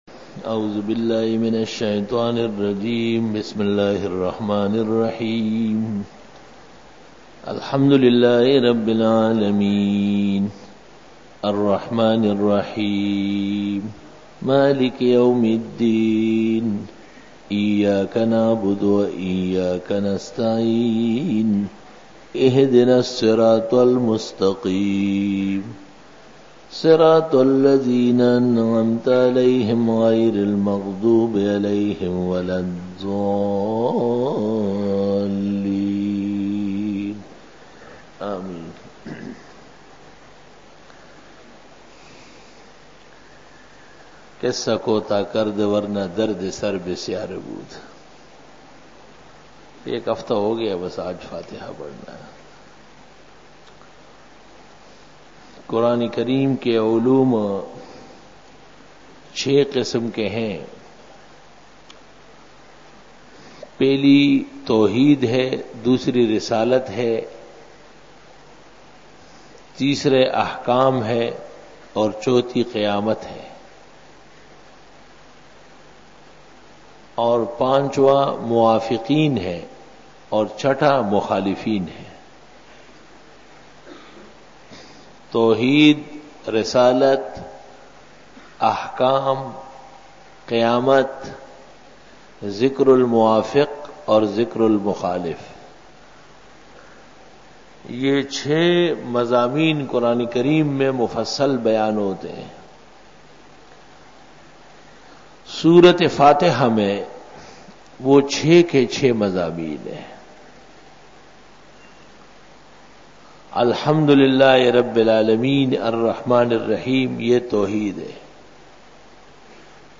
بسم اللہ الرحمن الرحیم پر کلام Bayan